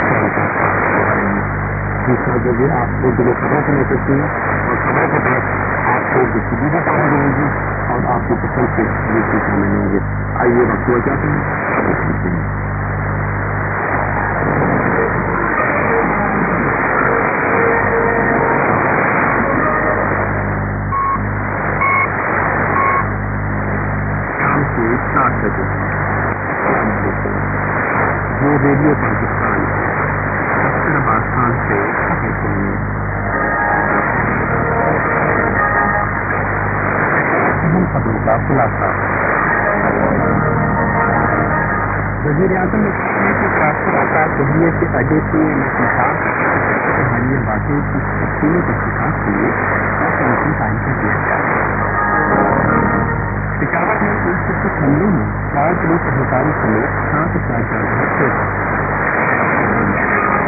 ・このＨＰに載ってい音声(ＩＳとＩＤ等)は、当家(POST No. 488-xxxx)愛知県尾張旭市で受信した物です。